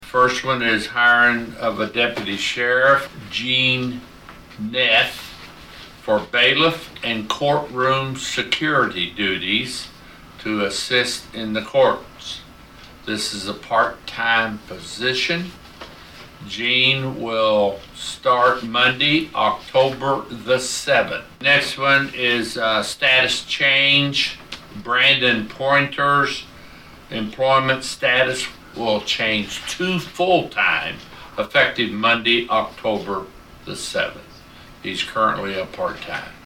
During a recent meeting of the Saline County Commission, commissioners were asked to give their approval to several new hires for the sheriff’s department.
Presiding Commissioner Kile Guthrey Jr. read off the first two requests.